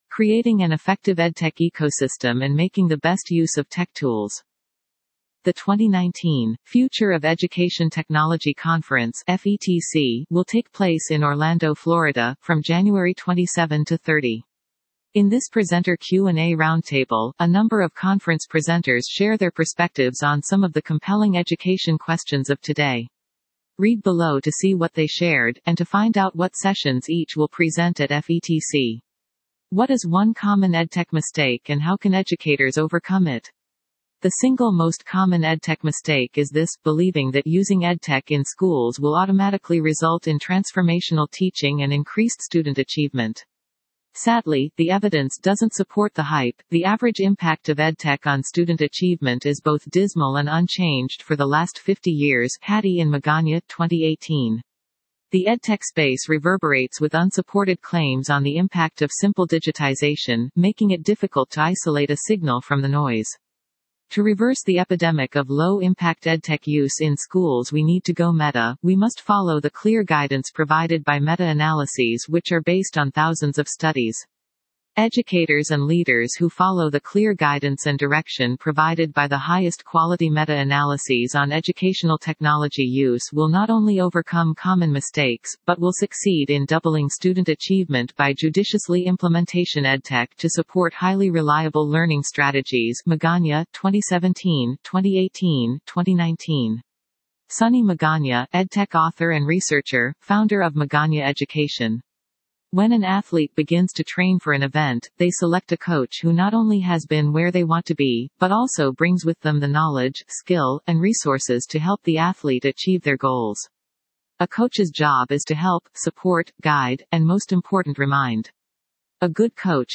In this Presenter Q&A Roundtable, a number of conference presenters share their perspectives on some of the compelling education questions of today on EdTech Ecosystem & Making the Best Use of Tech Tools.